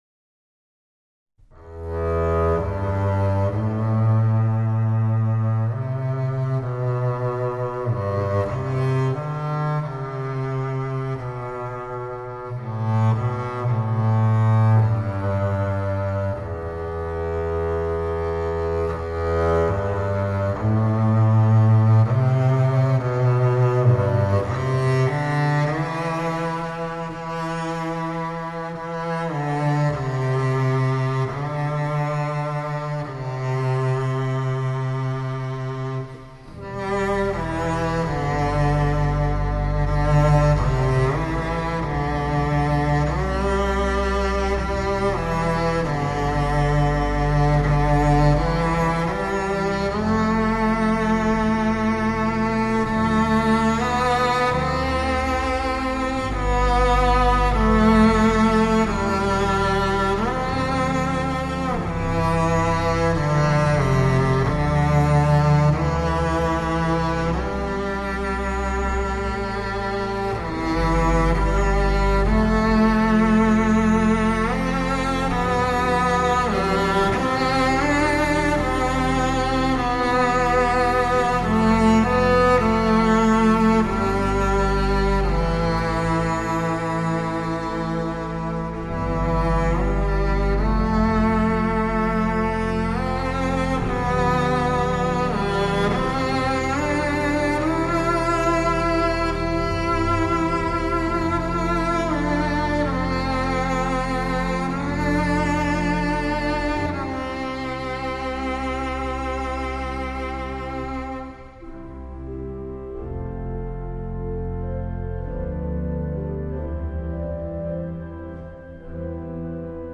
콘트라베이스의 귀재
Double Bass(CONTRABASS)
바이올린족(族) 중 가장 낮은 음을 내며, 첼로보다 한 옥타브 낮은 현악기.